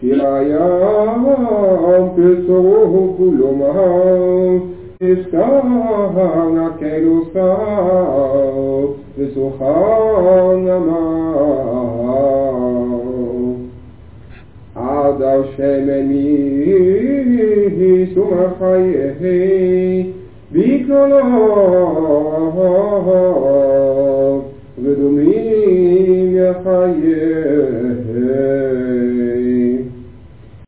op cassettebandjes